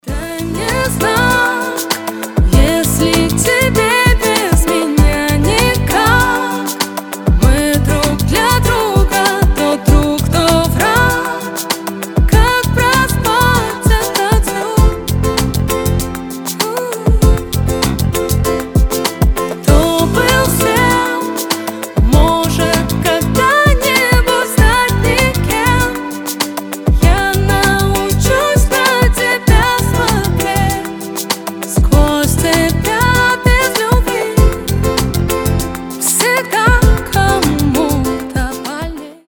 • Качество: 320, Stereo
женский голос
спокойные
медленные